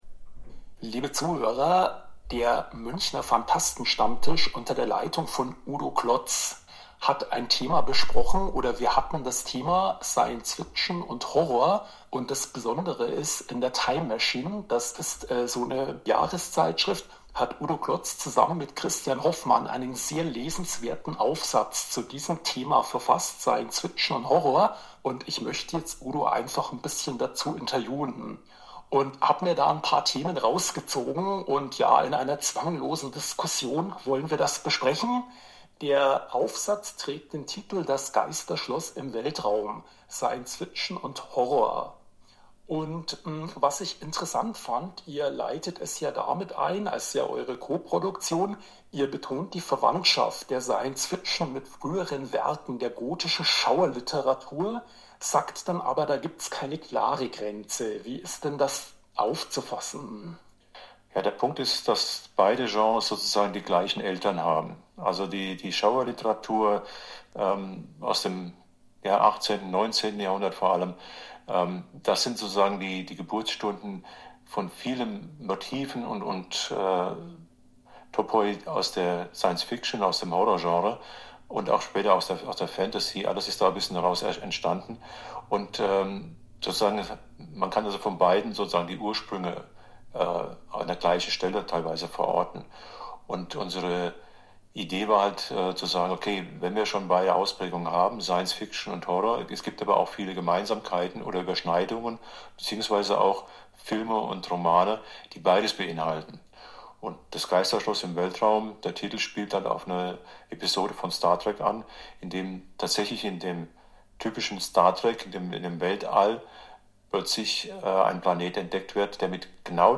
Von Zeit zu Zeit werden spezielle Themen oder Vorträge der Münchner "Phantasten" aufgezeichnet und online gestellt. Oft sind es Themen, die sich um theoretische Grundlagen des Genres handeln. Der Stammtisch hat keine feste Besetzung.